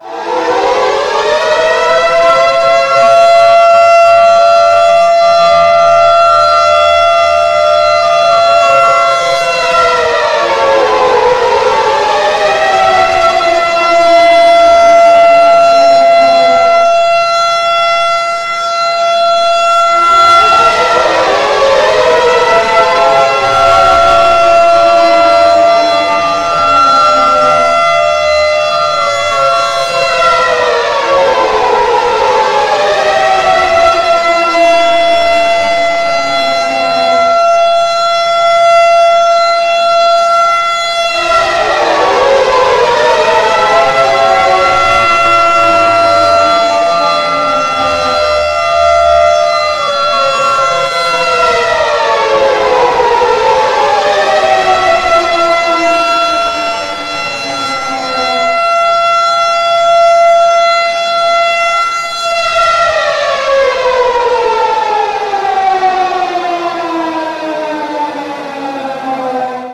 siren24_long.mp3